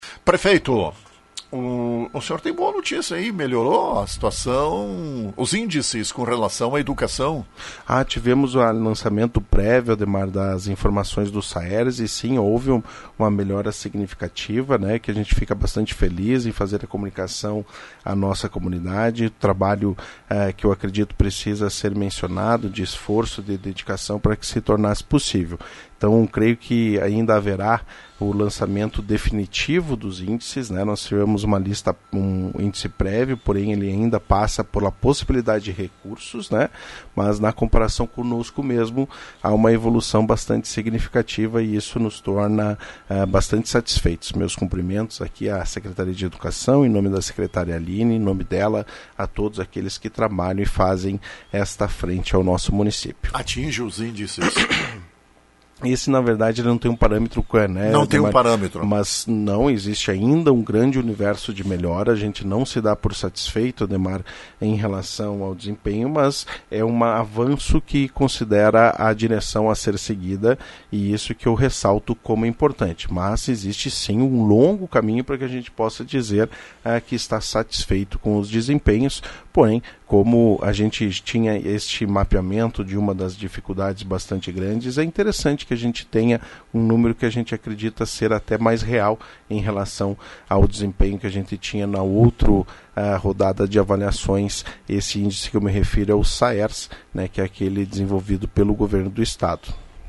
Informação transmitida pelo prefeito Gustavo Bonotto na manhã desta segunda-feira. Melhoraram os índices do Ensino Municipal. Prefeito saudou esse avanço.